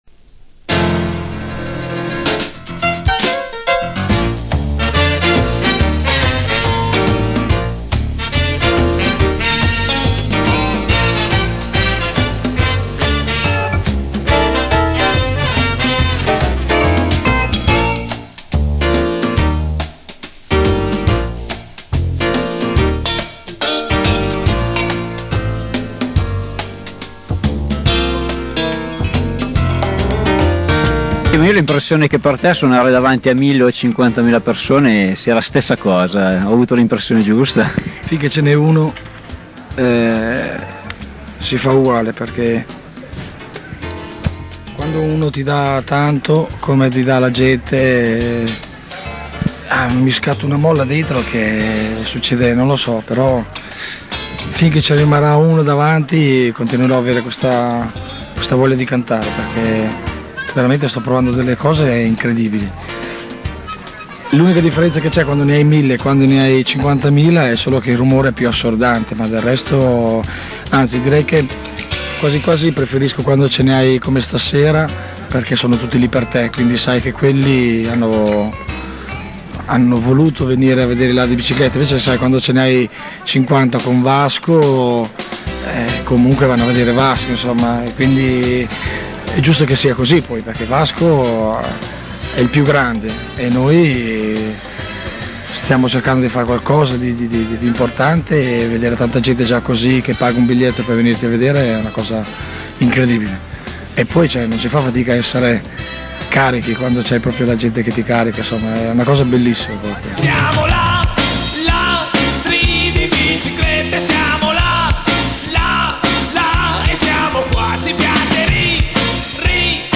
il ritorno dei "Ladri" in provincia: intervista e saluti (1990)
Cominciamo con la registrazione di un incontro dell’estate 1990, in occasione di un concerto dei Ladri a Quarti di Pontestura.